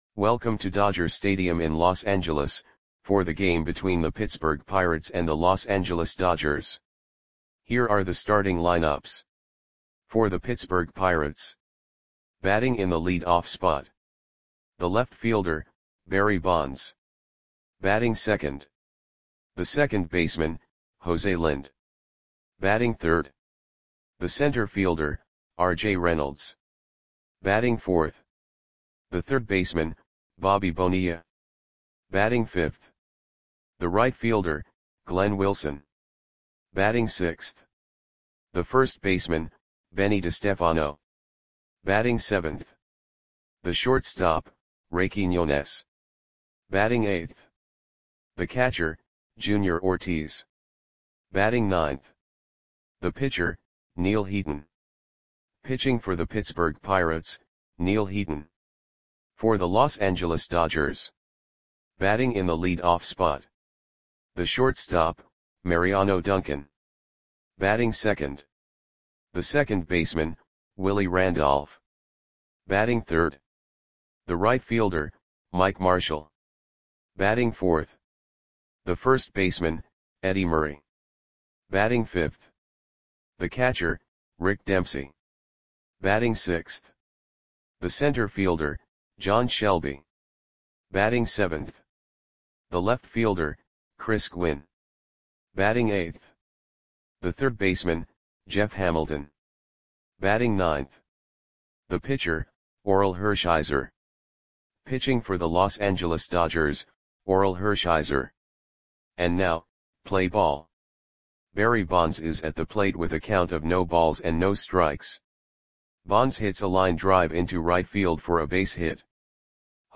Lineups for the Los Angeles Dodgers versus Pittsburgh Pirates baseball game on May 2, 1989 at Dodger Stadium (Los Angeles, CA).
Click the button below to listen to the audio play-by-play.